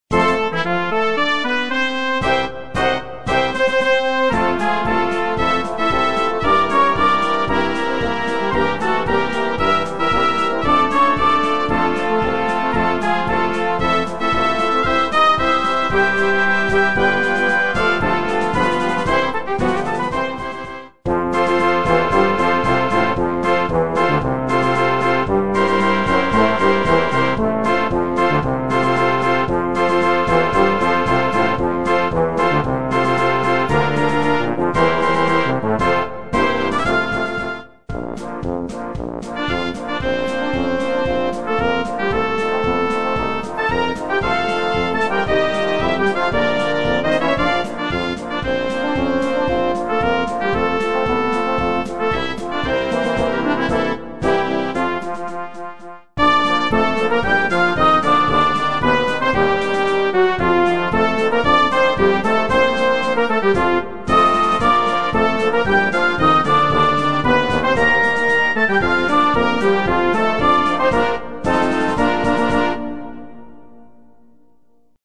Blasmusik:
Märsche: